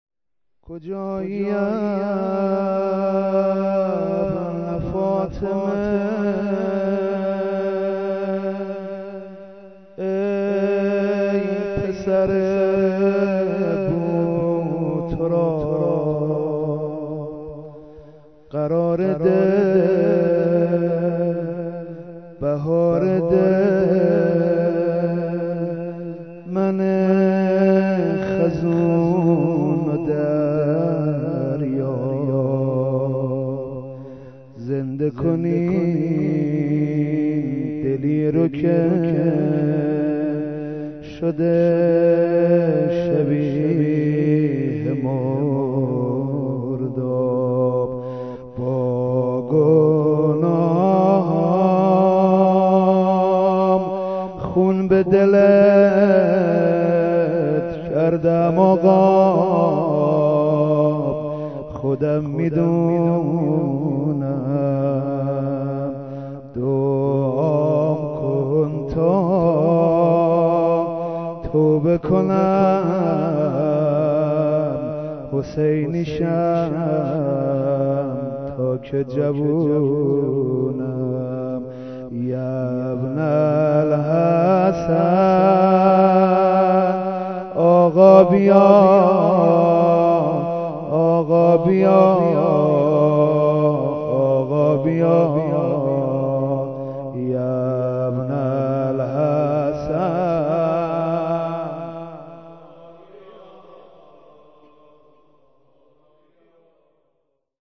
زمزمه شهادت حضرت رقیه (س) -( سلام بابا، بالاخره، یادی ام از ما کردی )